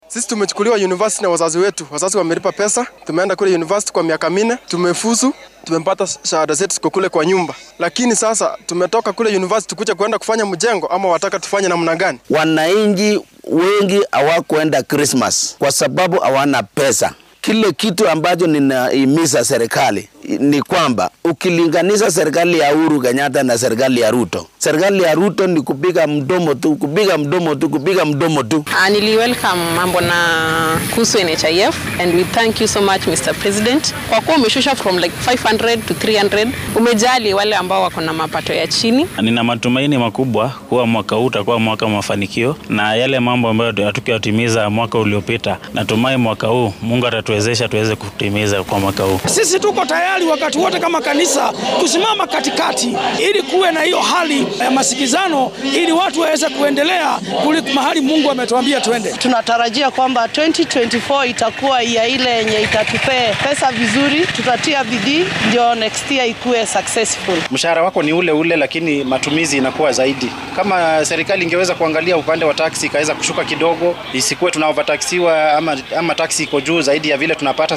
DHAGEYSO:Shacabka oo ka falceliyay khudbadii madaxweynaha ee sanadkan cusub